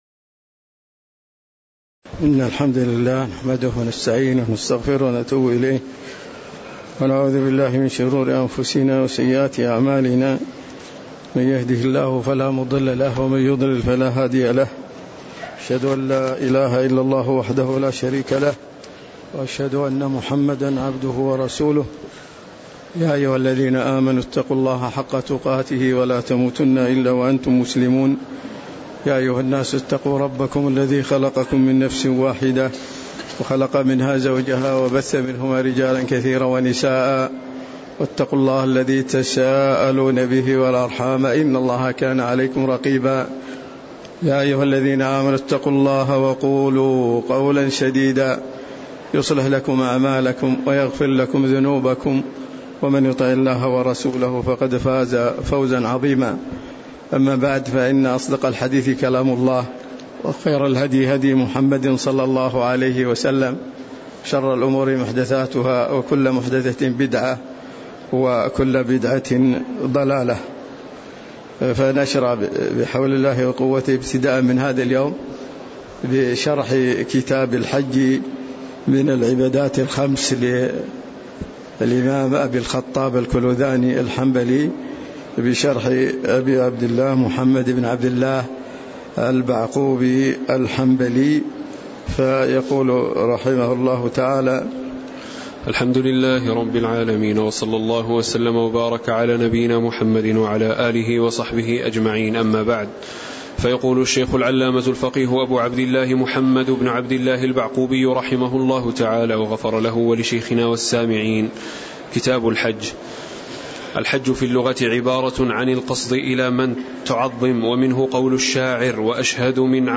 تاريخ النشر ٢٨ ذو القعدة ١٤٤٤ هـ المكان: المسجد النبوي الشيخ